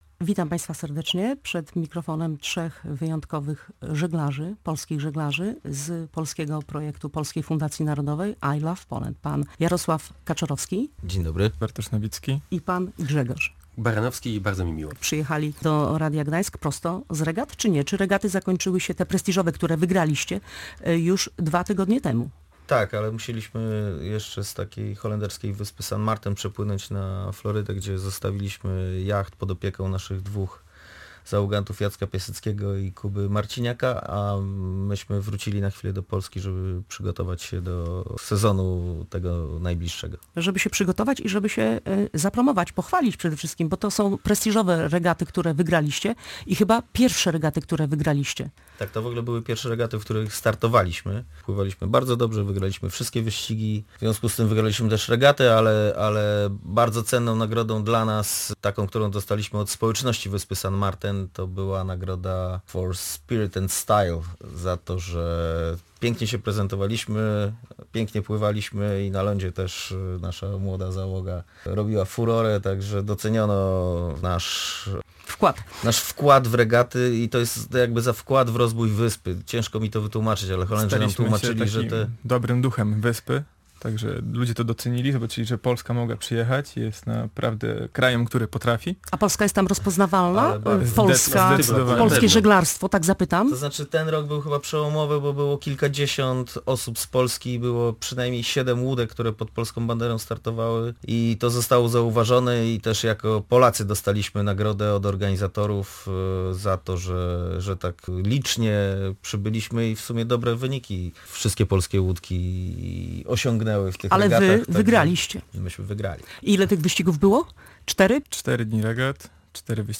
Prosto z Florydy żeglarze przyjechali do Trójmiasta i byli gośćmi Radia Gdańsk.